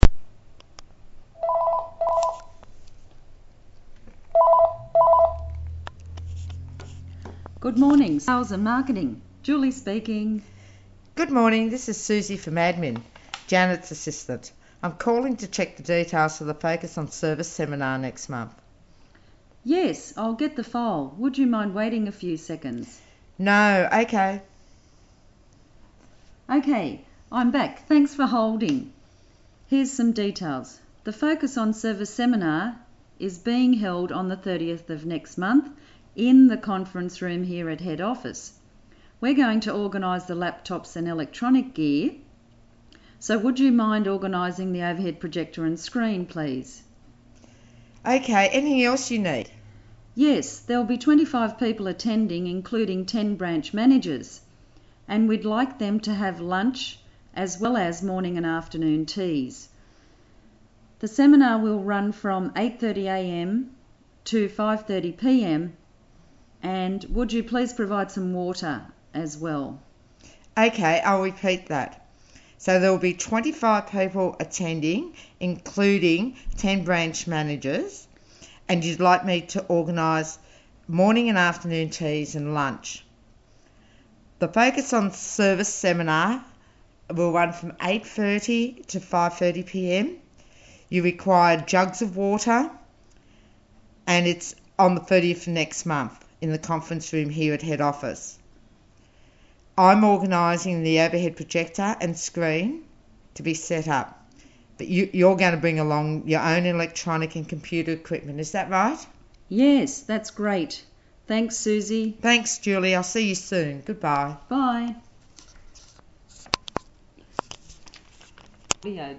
phone conversation